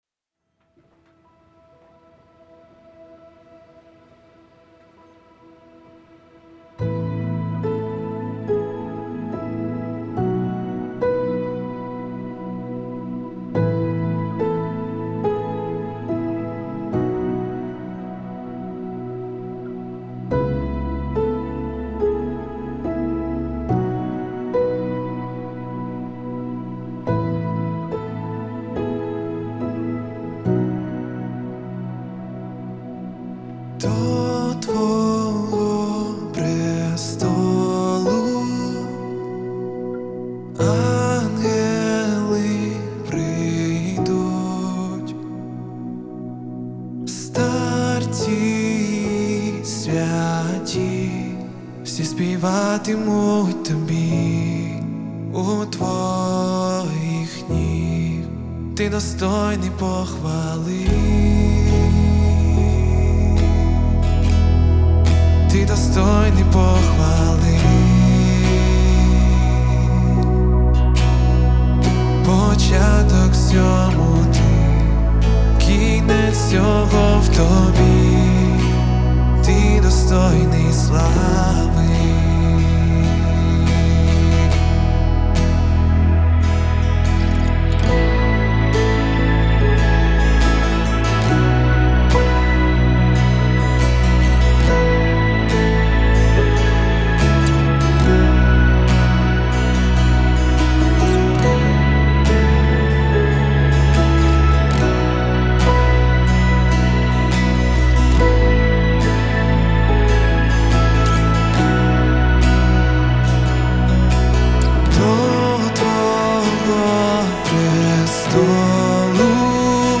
393 просмотра 201 прослушиваний 23 скачивания BPM: 142